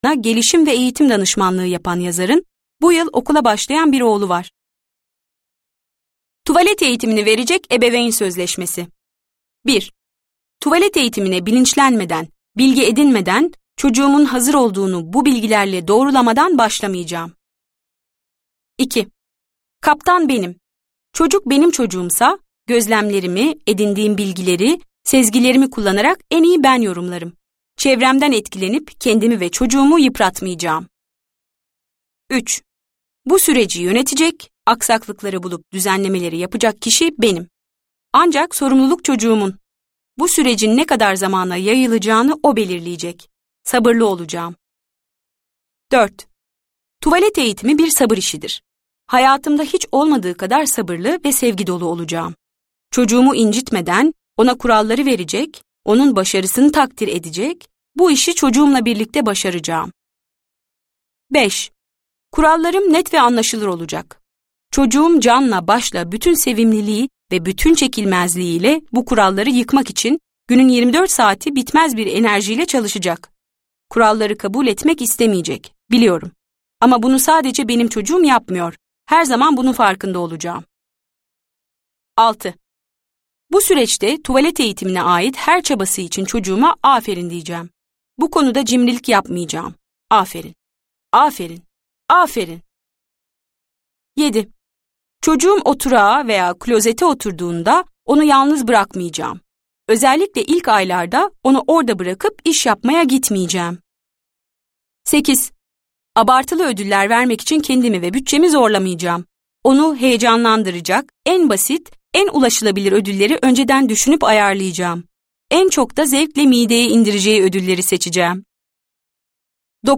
Anne Çişim Var! - Seslenen Kitap